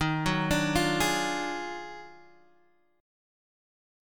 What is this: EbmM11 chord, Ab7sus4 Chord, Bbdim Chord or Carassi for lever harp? EbmM11 chord